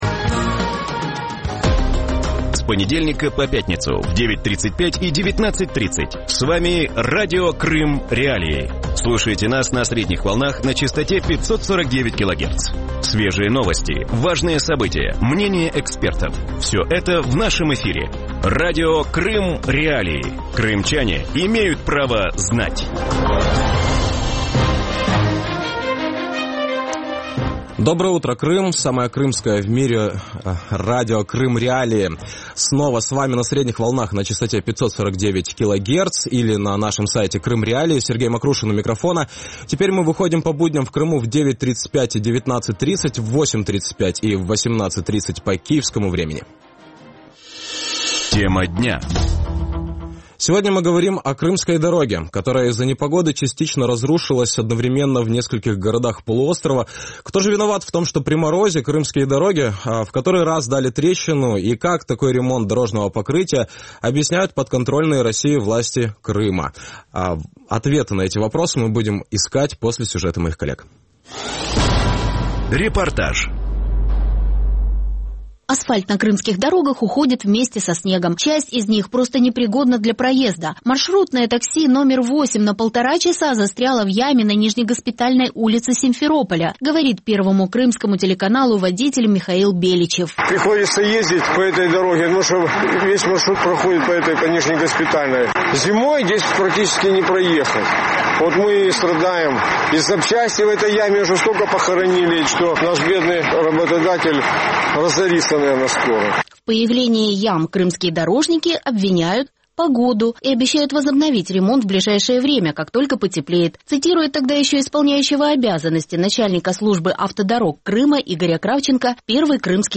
Утром в эфире Радио Крым.Реалии говорят о крымских дорогах, которые из-за непогоды частично разрушились одновременно в нескольких городах полуострова. Кто виноват в том, что при морозе дороги в который раз дали трещину? И как такой ремонт дорожного покрытия объясняют подконтрольные России власти полуострова?